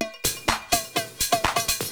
DRUMLOOP067_PROGR_125_X_SC3.wav